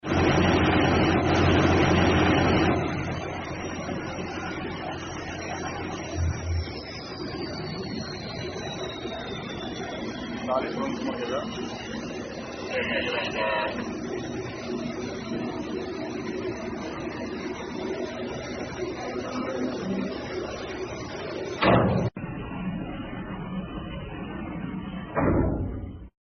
FPV TUG BOAT BATUBARA DI sound effects free download